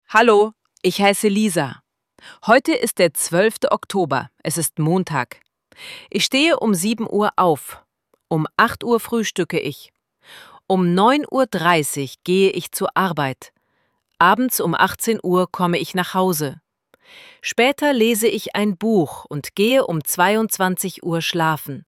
IZGOVOR – PRIČA:
ElevenLabs_Text_to_Speech_audio-46.mp3